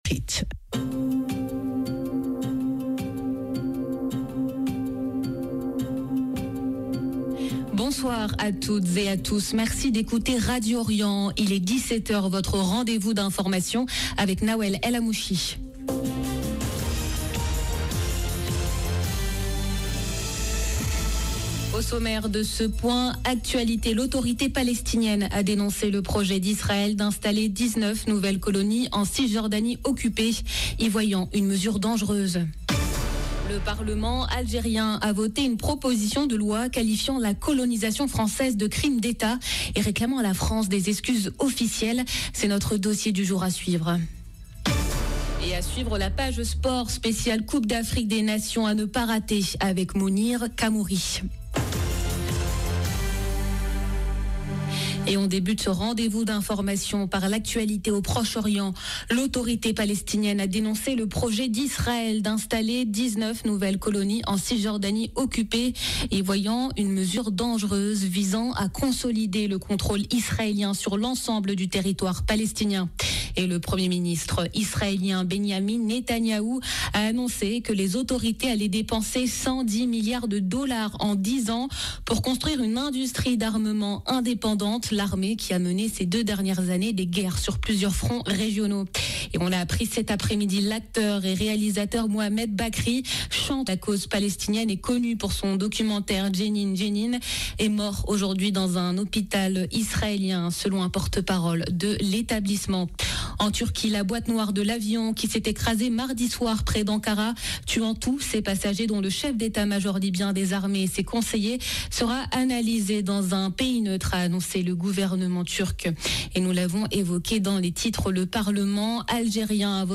JOURNAL DE 17H